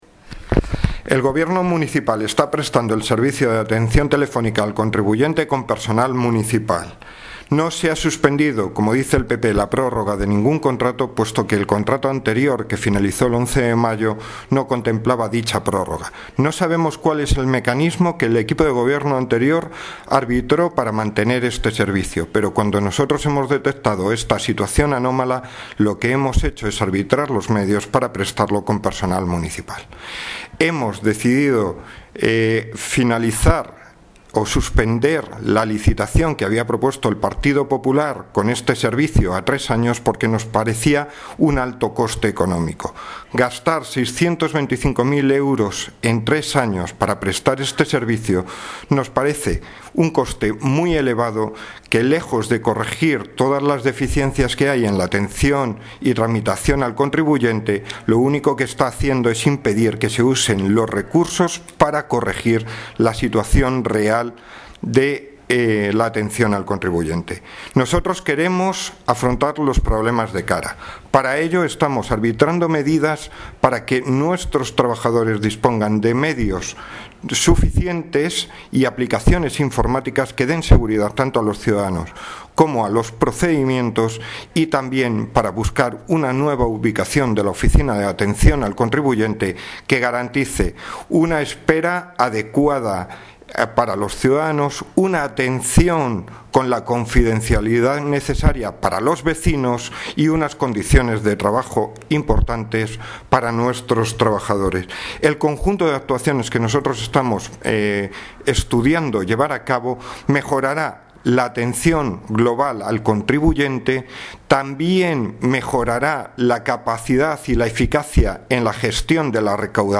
Pleno Municipal de Septiempre: El gobierno municipal está prestando el servicio de atención telefónica al contribuyente con personal municipal.
Audio - Javier Gómez (Concejal e Hacienda, Patrimonio, Ráegimen Interno y Contratación) Sobre teléfono contribuyente